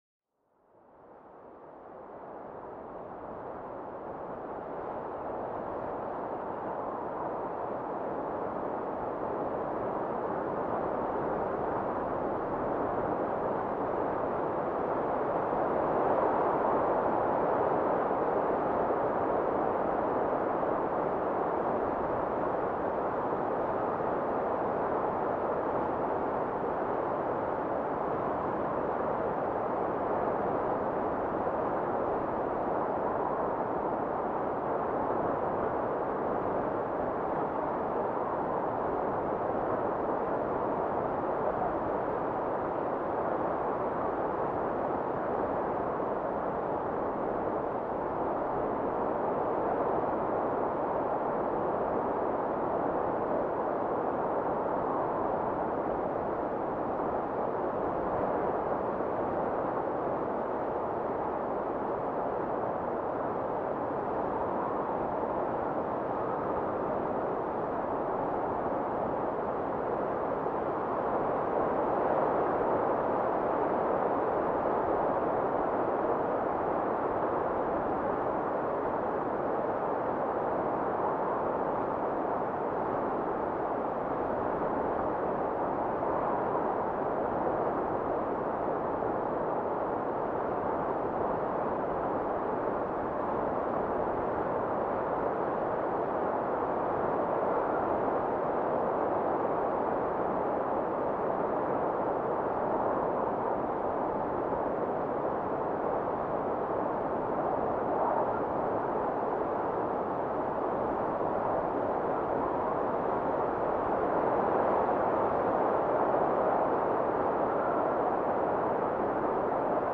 Sumérgete en el corazón de un bosque donde el viento susurra entre las ramas, acariciando suavemente las hojas en una melodía natural y suave. Este episodio te invita a un viaje sónico donde cada ráfaga cuenta una historia antigua, arrullando la mente en una tranquilidad sin igual.
A través de grabaciones de alta calidad, crea un espacio sonoro donde el bienestar y la paz interior reinan supremos.